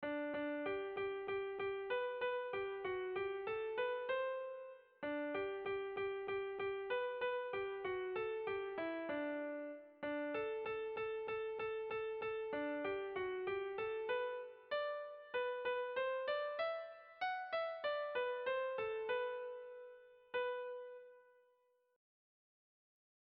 Irrizkoa
Zortziko txikia (hg) / Lau puntuko txikia (ip)
A1A2BD